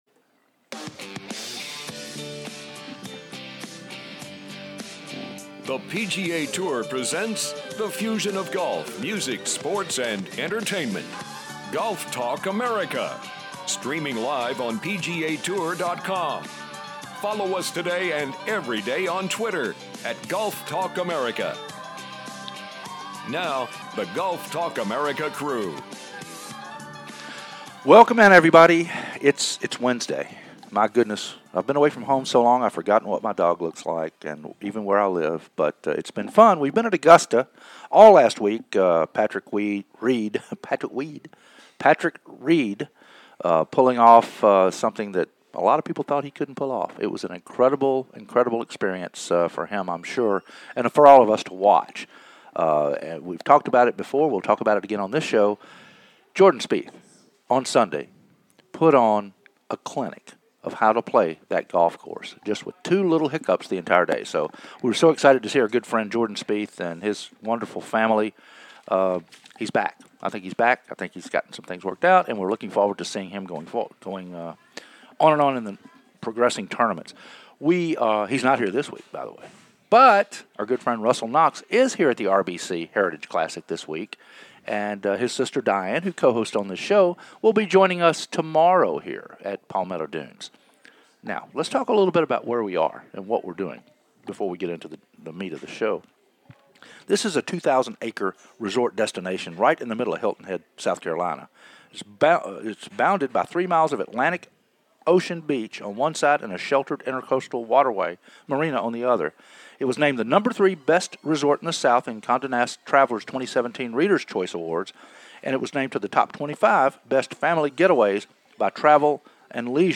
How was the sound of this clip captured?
"LIVE" From The Robert Trent Jones Course at The Palmetto Dunes Resort